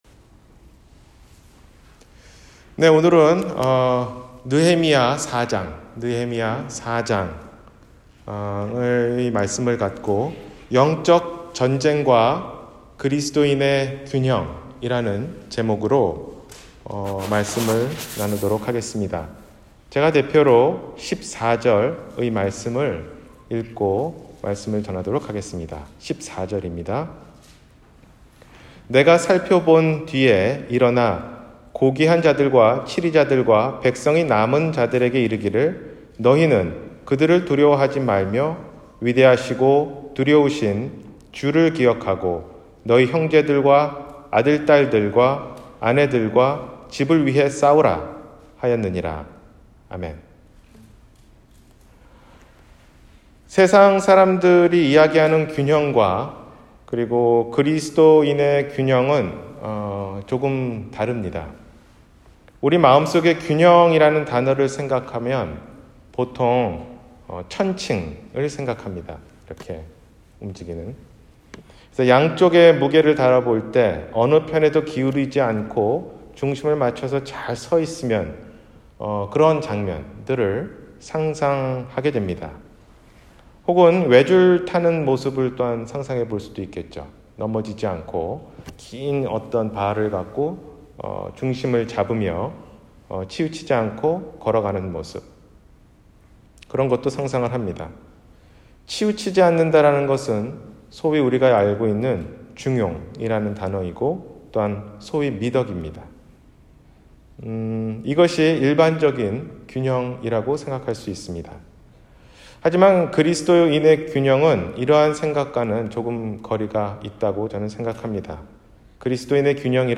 영적 전쟁과 그리스도인의 균형 (느헤미야 4장) – 주일설교 – 갈보리사랑침례교회